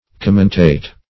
Commentate \Com"men*tate\, v. t. & i. [L. commentatus, p. p. of